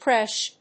/kréʃ(米国英語), kreʃ(英国英語)/